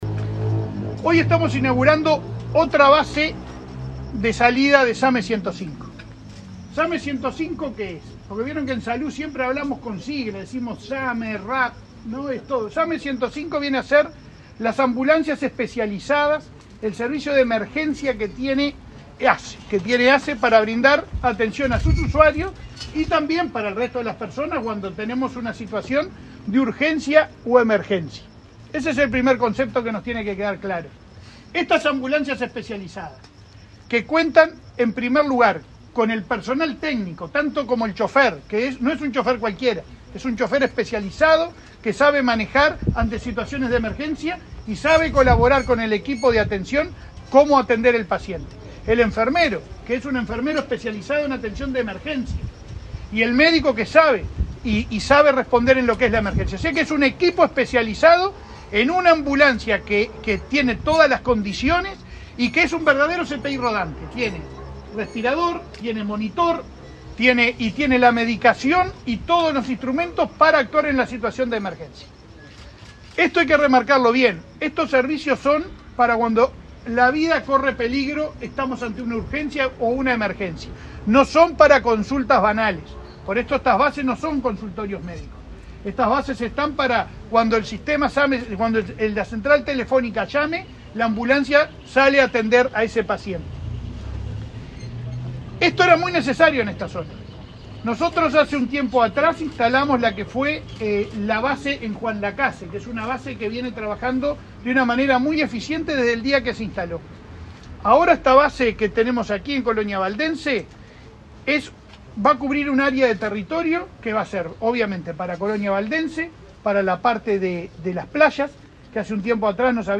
Palabras del presidente de ASSE, Leonardo Cipriani
Palabras del presidente de ASSE, Leonardo Cipriani 19/12/2023 Compartir Facebook X Copiar enlace WhatsApp LinkedIn El presidente de la Administración de los Servicios de Salud del Estado (ASSE), Leonardo Cipriani, participó, este martes 19 en Colonia, en la inauguración una base del Sistema de Atención Médica de Emergencia (SAME) 105 en la localidad de Colonia Valdense.